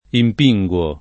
impinguare v.; impinguo [ imp &jgU o ]